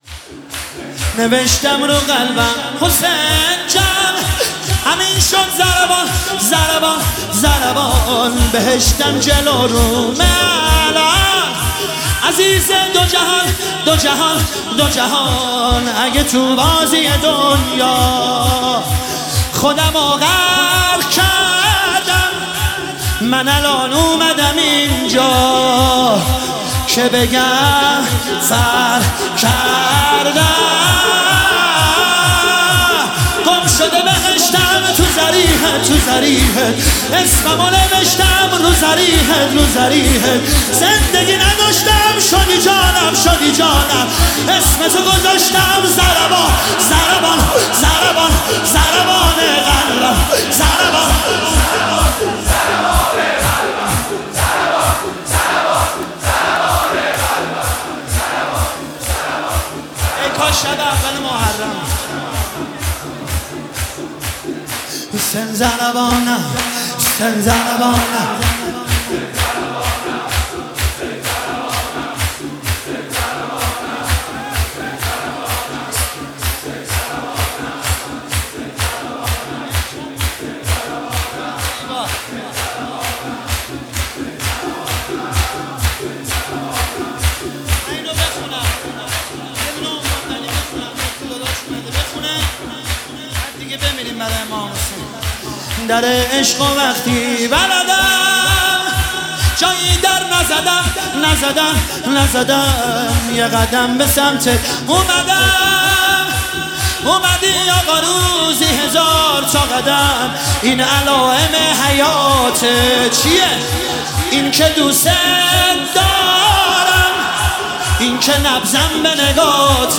مداحی شور احساسی زیبا